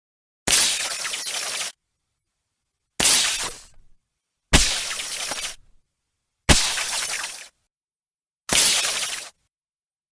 Звуки Человека-Паука, паутины
Паутина создаваемая человеком пауком